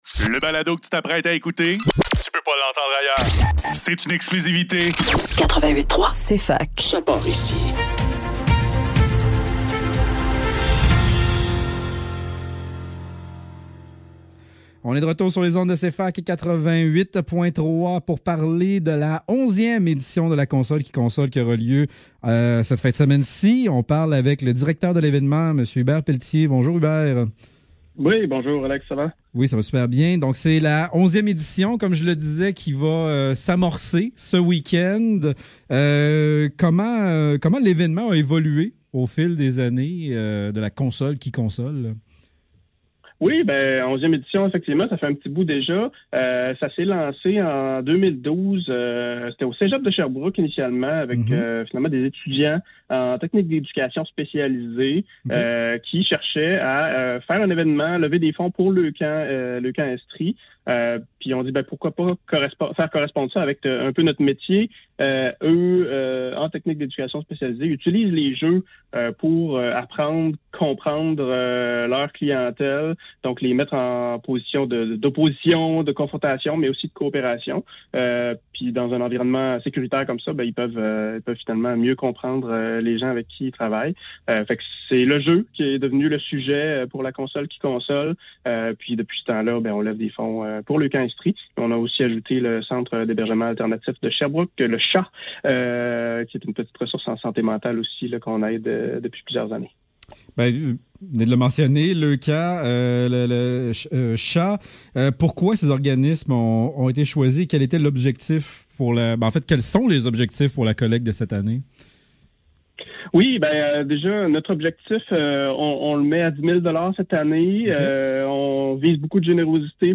Le Neuf - Entrevue